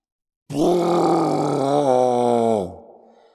horror
Dinosaur Roar 1